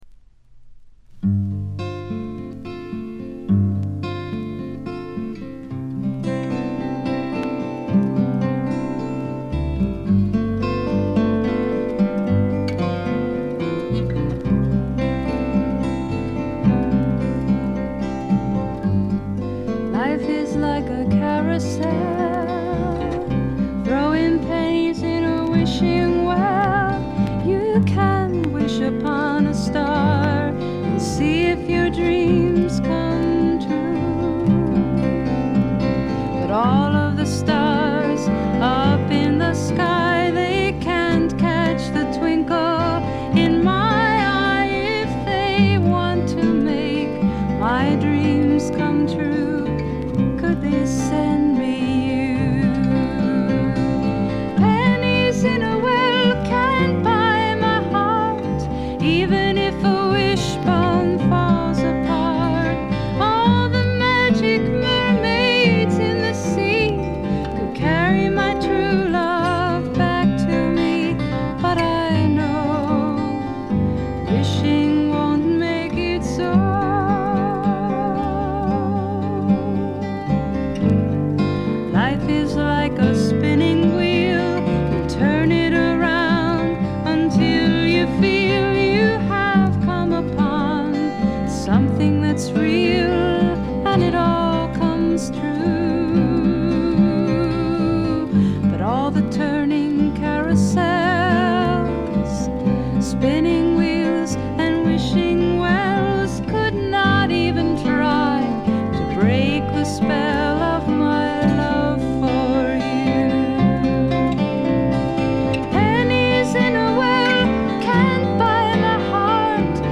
ほぼ全曲自作もしくは共作で、ギター弾き語りが基本の極めてシンプルな作りです。
試聴曲は現品からの取り込み音源です。